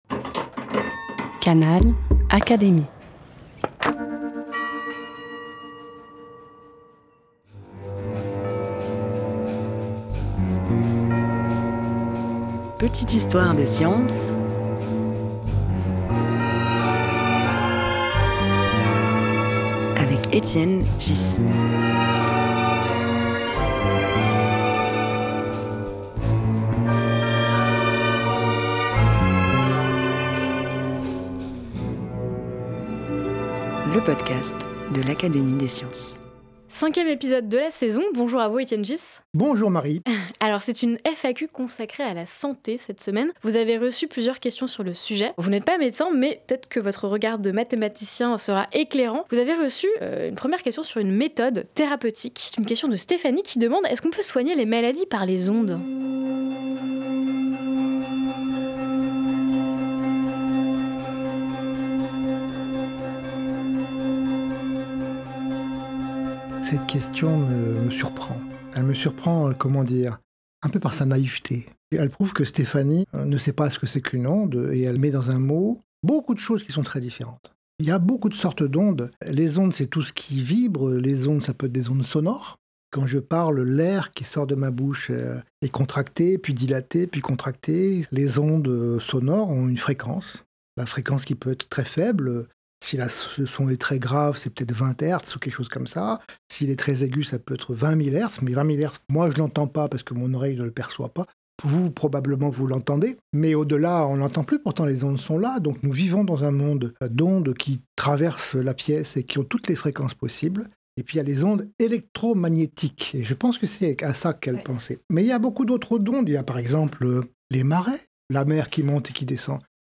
Un podcast proposé par l'Académie des sciences, animé par Étienne Ghys.